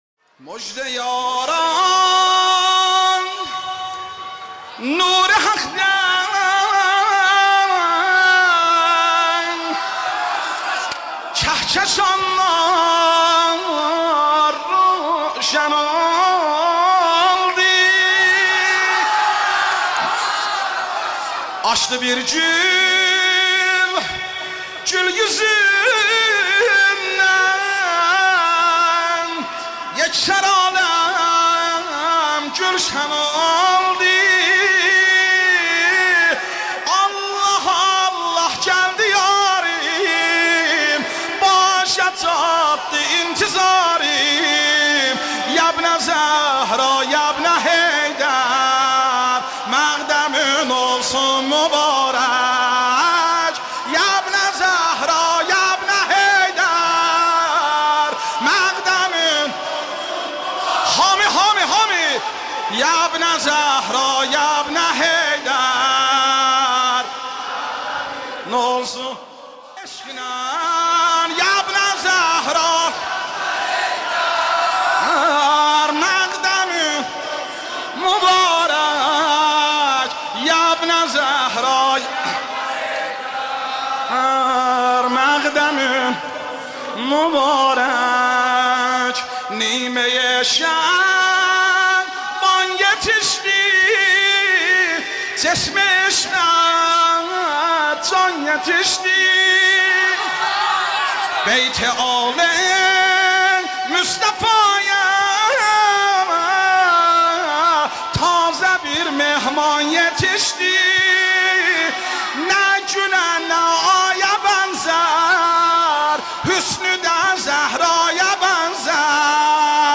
به مناسبت ولادت امام عصر(عج)